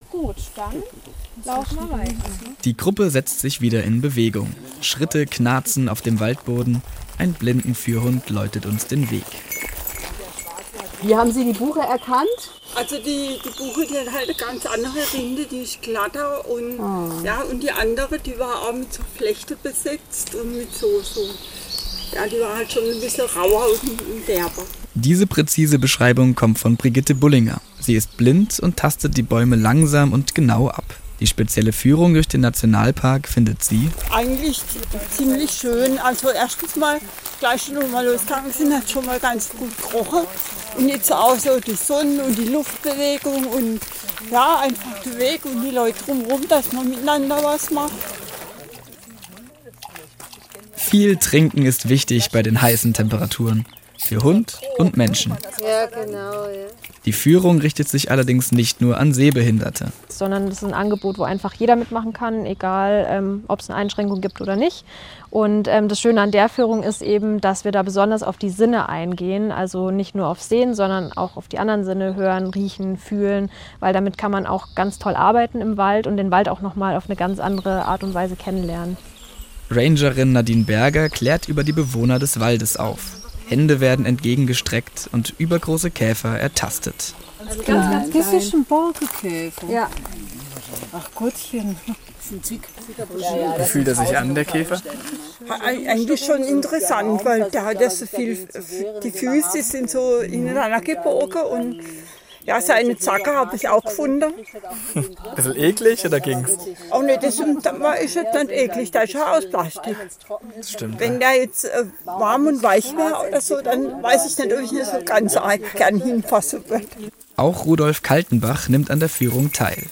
Auf einer vier Kilometer langen Tour absolvierte die Gruppe eine Reihe von besonderen Stationen.